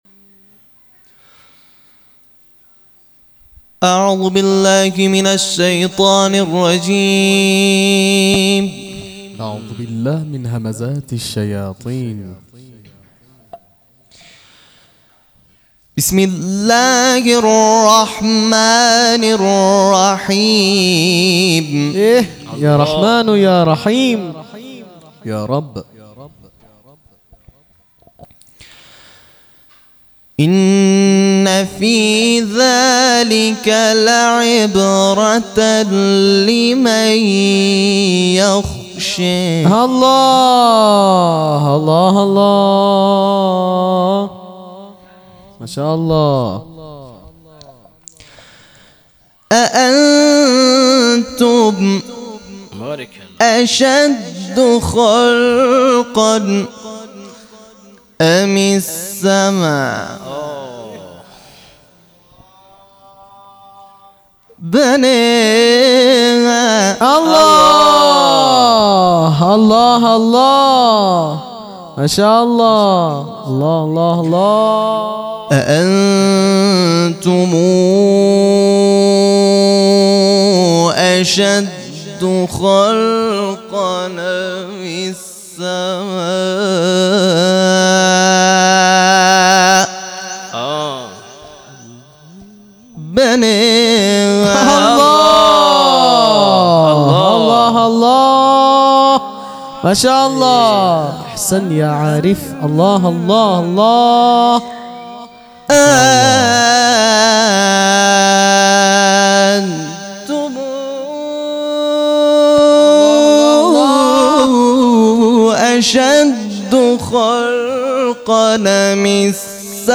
تلاوت قرآن ، سوره نازعات